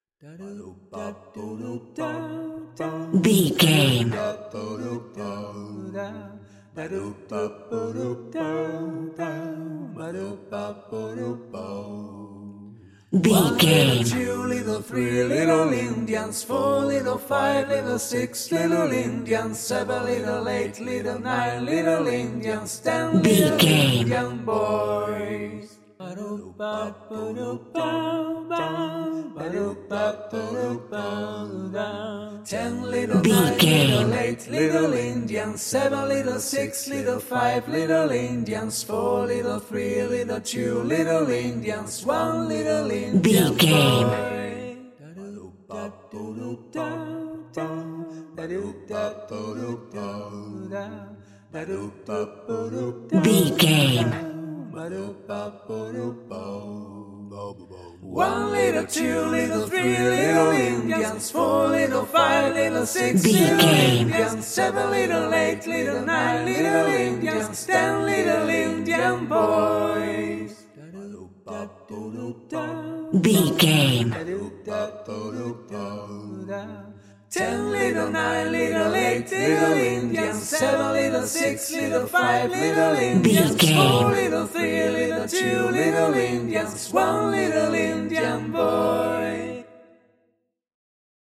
Ionian/Major
fun
groovy